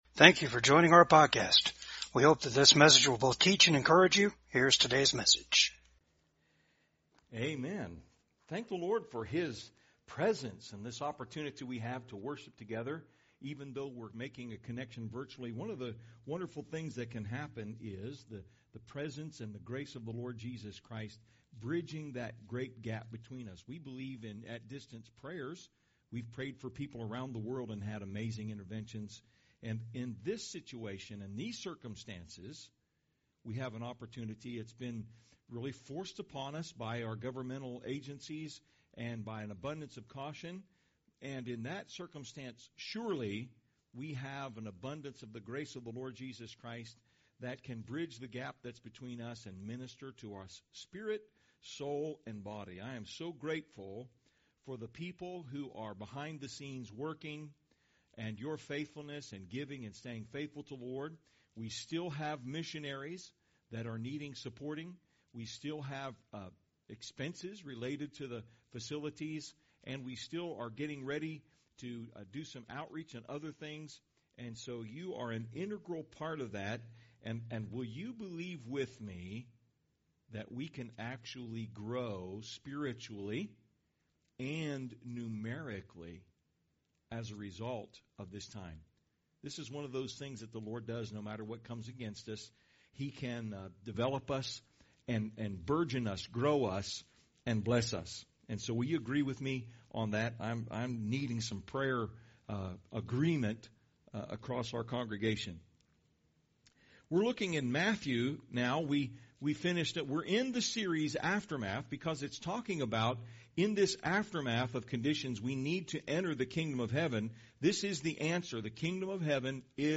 Service Type: VCAG SUNDAY SERVICE WHAT IS YOUR HEART CONDITION?